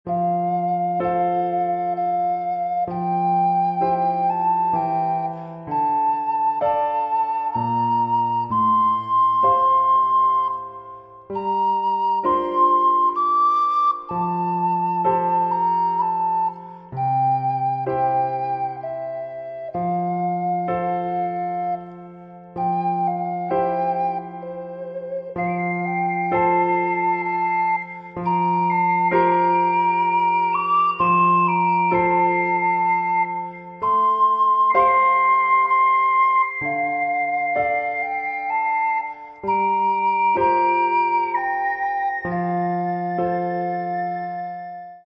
オカリナの音色は自然の息吹。